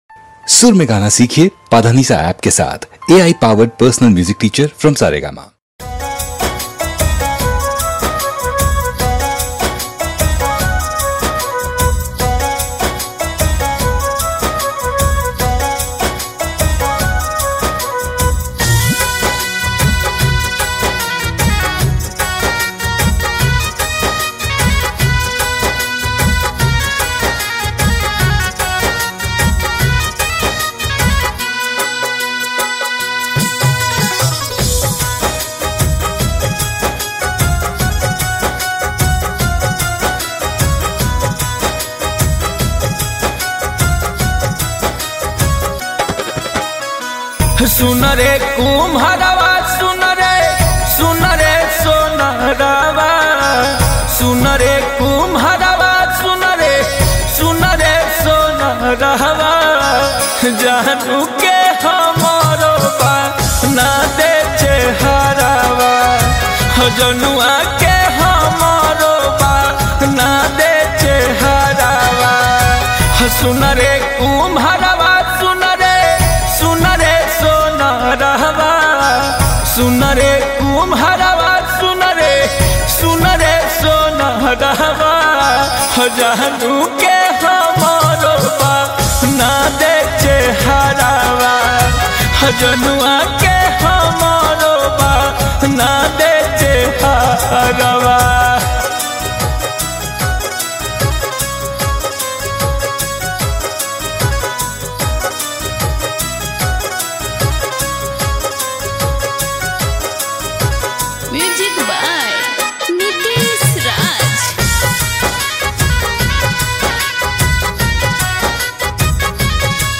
Category: New Bhojpuri Mp3 Songs